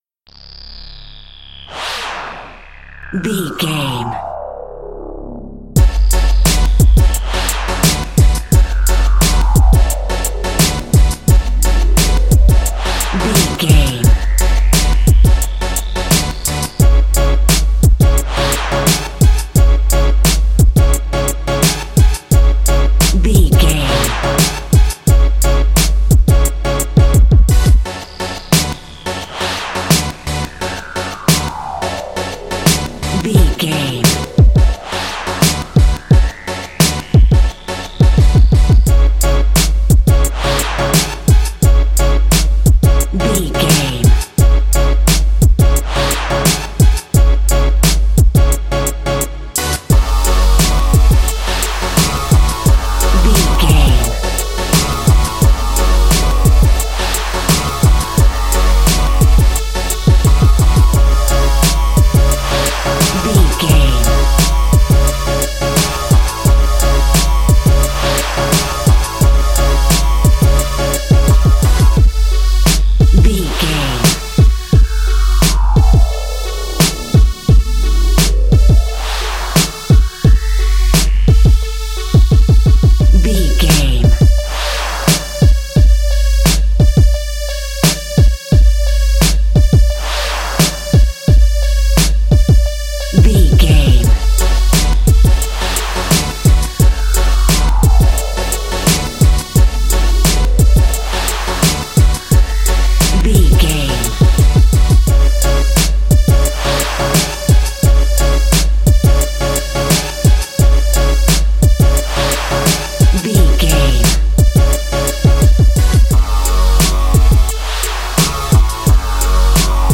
Aeolian/Minor
F#
drum machine
synthesiser
electric piano
hip hop
Funk
neo soul
acid jazz
energetic
bouncy
funky